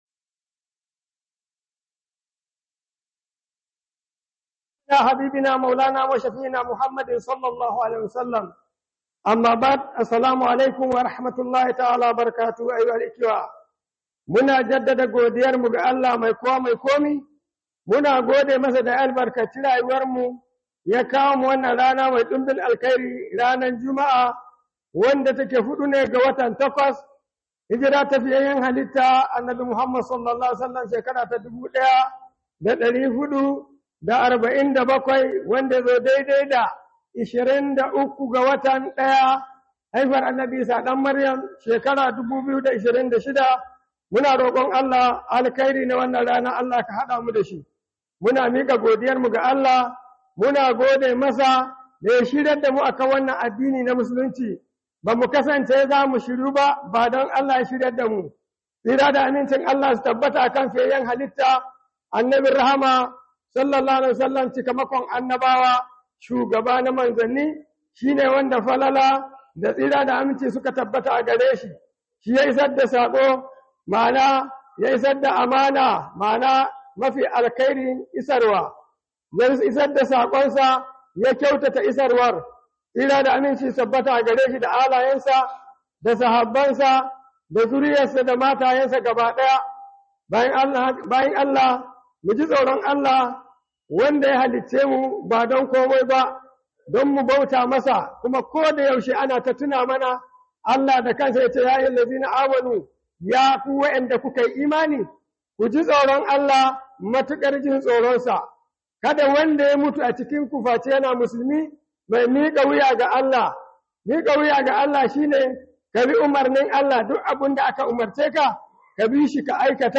Khudubar Sallar Juma'a by JIBWIS Ningi
Khuduba